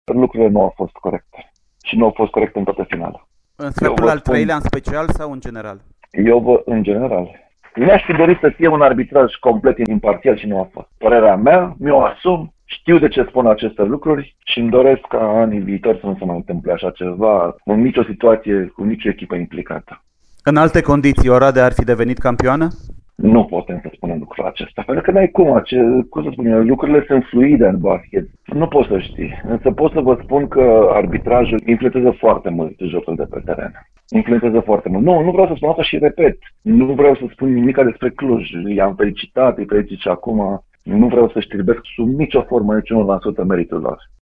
interviu pentru Radio Timișoara